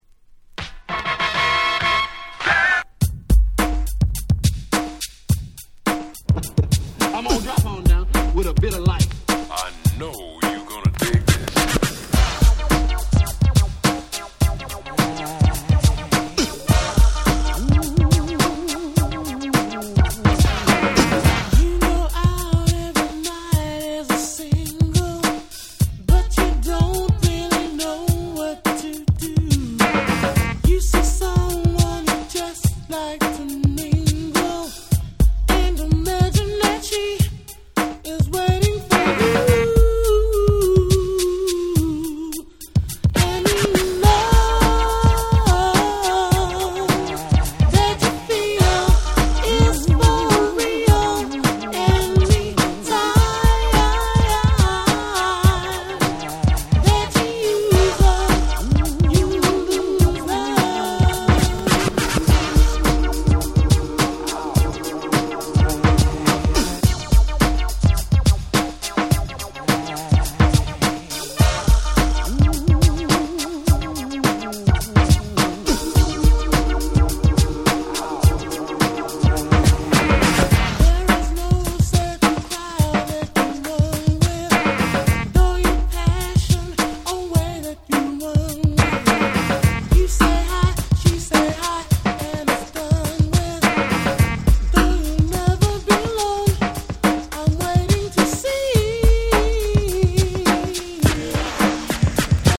88' Very Nice UK R&B / Break Beats !!